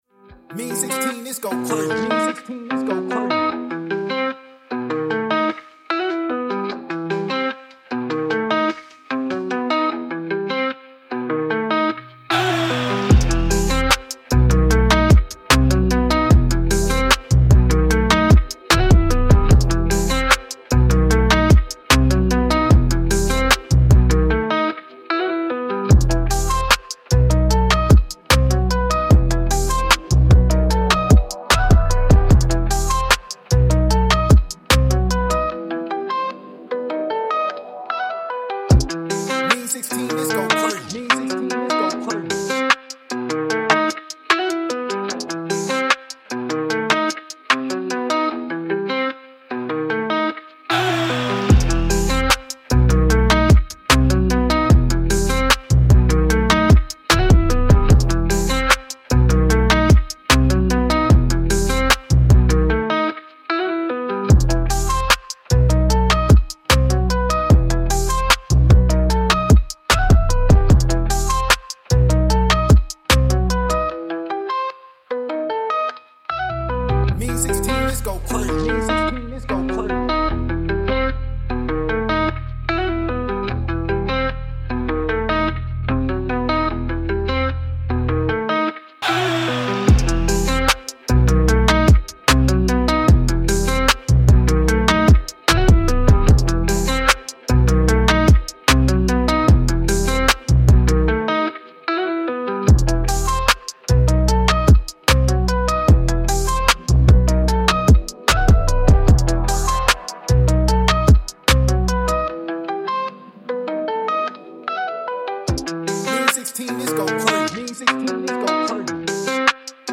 TRAP BEAT
E-Min 148-BPM